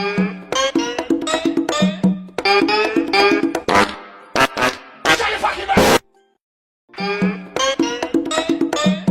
Sybau Fart Efecto de Sonido Descargar
Sybau Fart Botón de Sonido